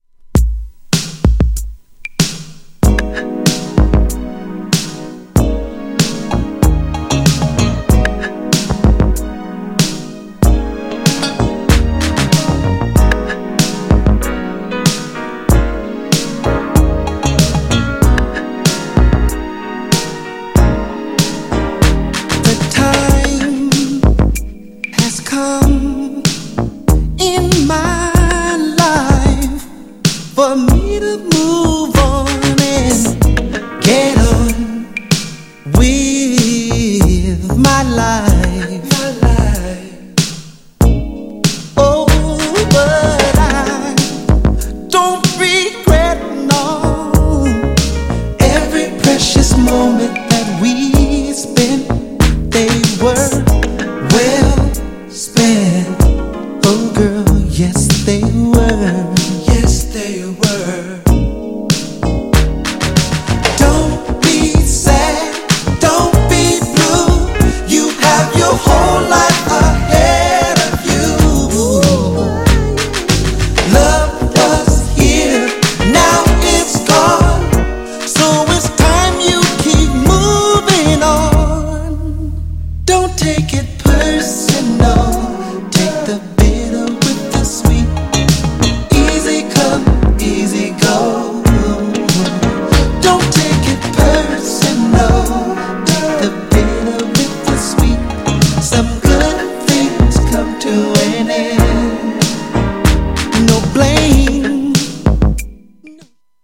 GENRE R&B
BPM 91〜95BPM
# JAZZY
# アーバン # ブラコン # ミディアム # 男性VOCAL_R&B